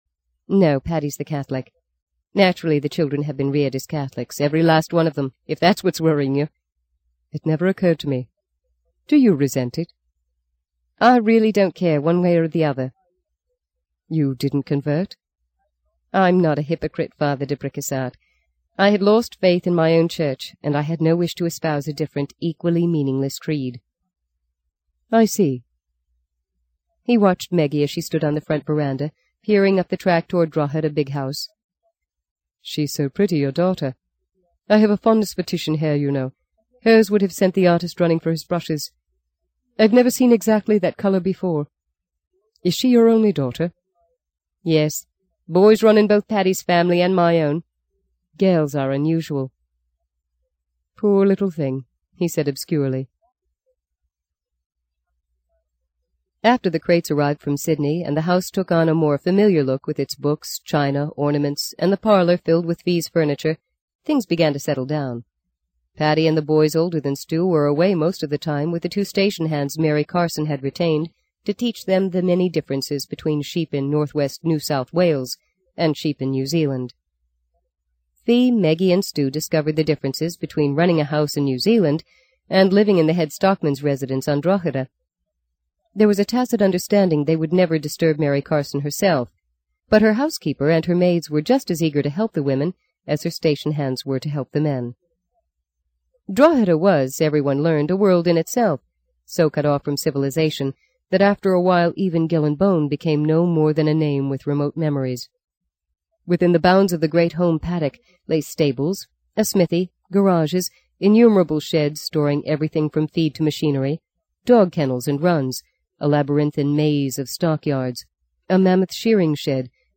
在线英语听力室【荆棘鸟】第四章 02的听力文件下载,荆棘鸟—双语有声读物—听力教程—英语听力—在线英语听力室